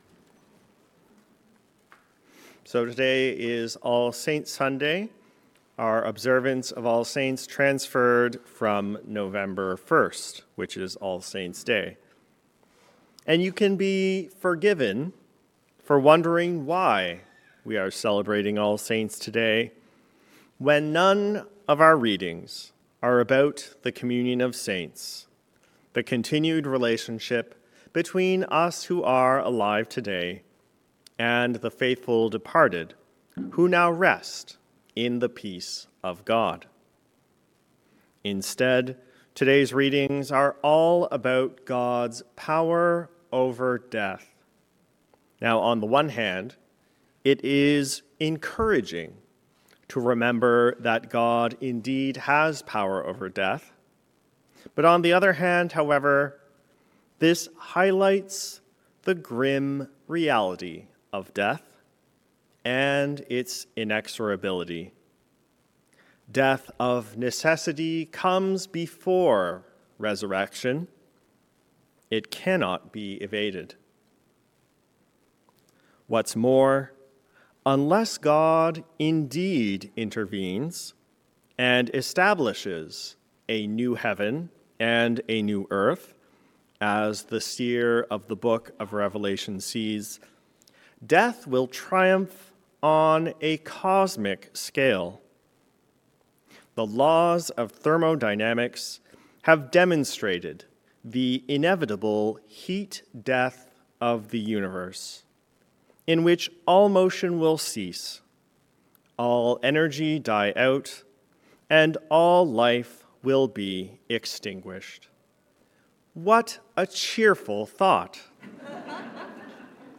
In the face of death. A Sermon for All Saints’ Day